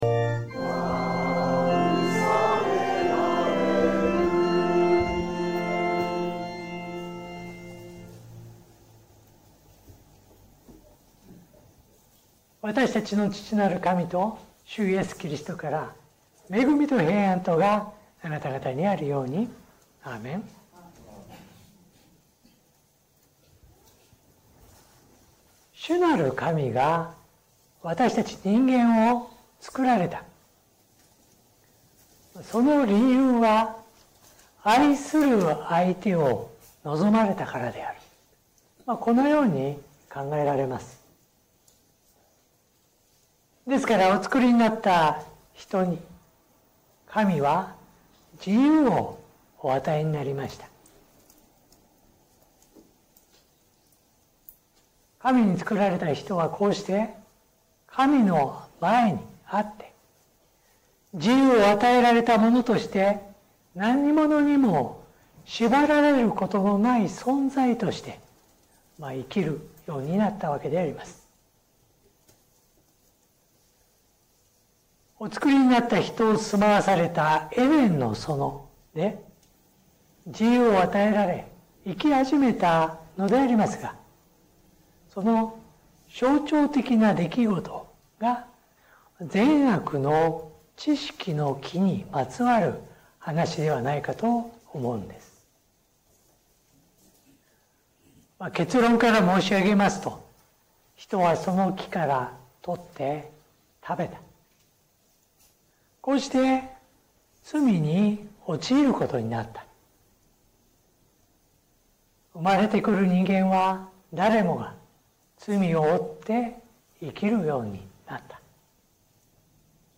説教音声 最近の投稿 2026年3月22日 礼拝・四旬節第5主日 3月22日 「死んでも生きる？」